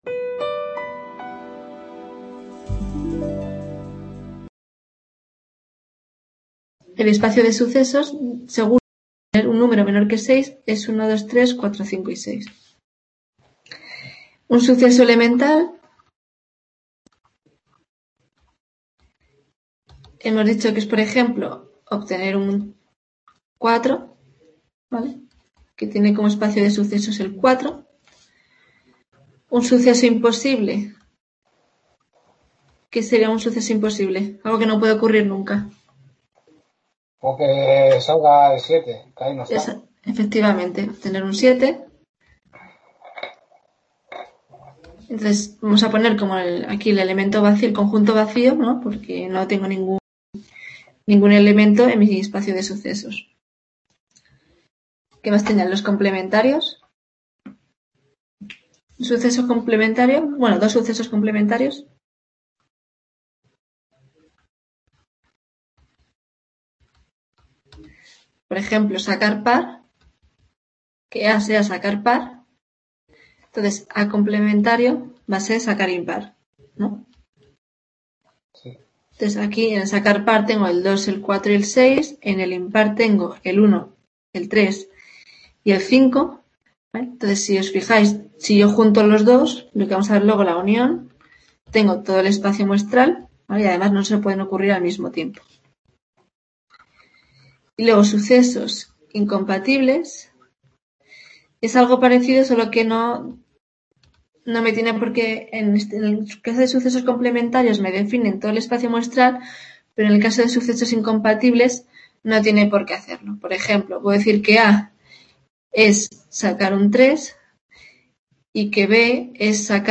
Tutoria Estadistica Basica- Calculo Probabilidad | Repositorio Digital